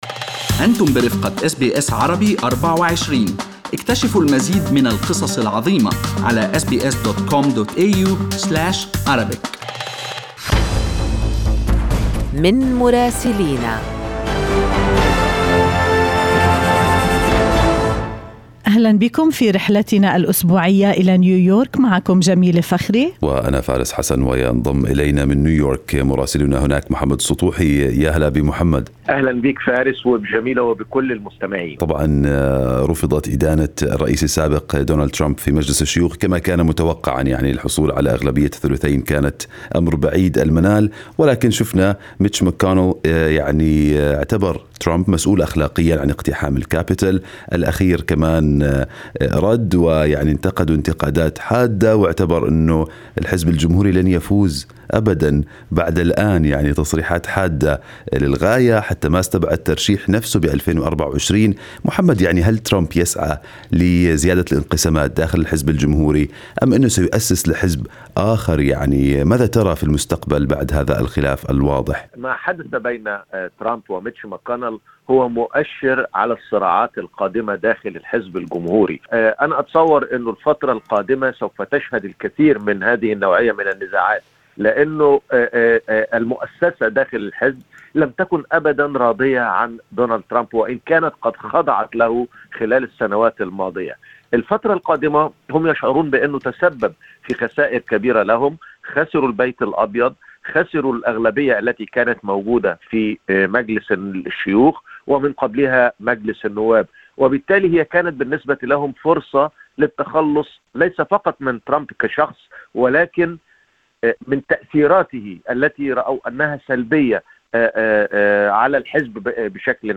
من مراسلينا: أخبار الولايات المتحدة الأمريكية في أسبوع 18/2/2021